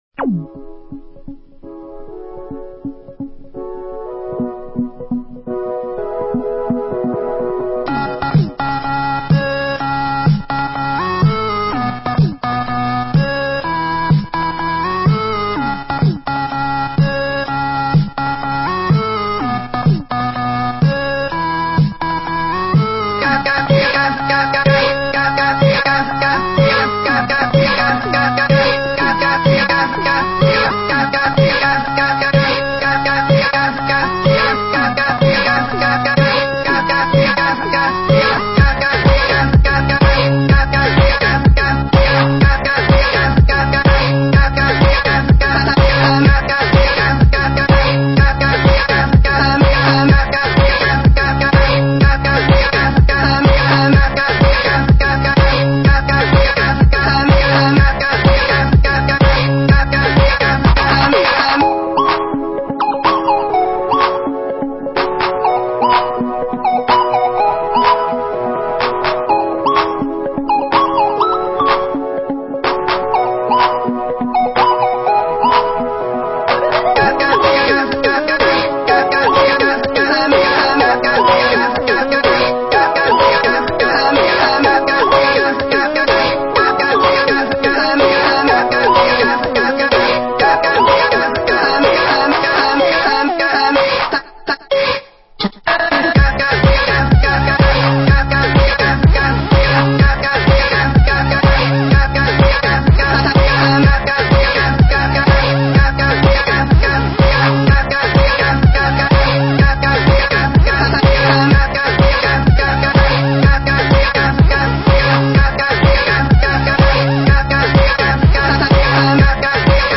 *** друзья необычный (electro house)